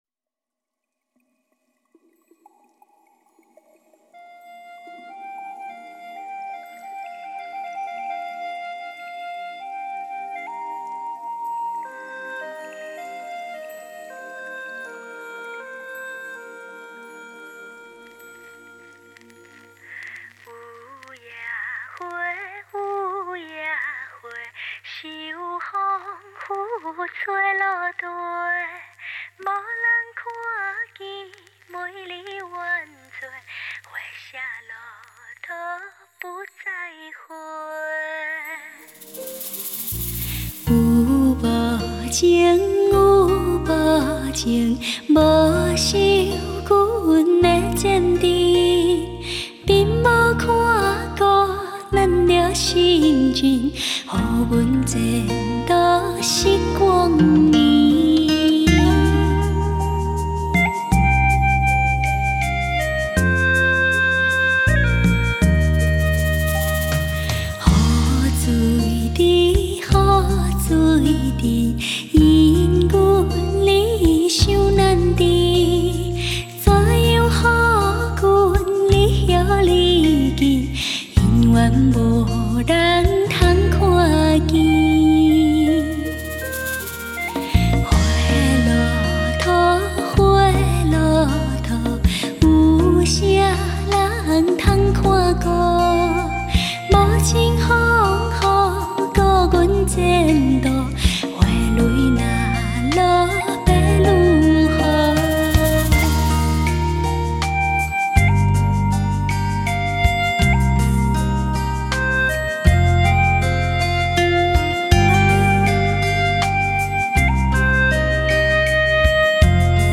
配乐经典优美，歌声甜美醉人。